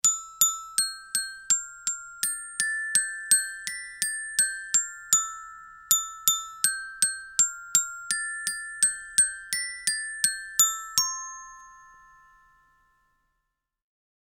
Melodía sencilla interpretada con un carillón
idiófono instrumento musical percusión campanilla carillón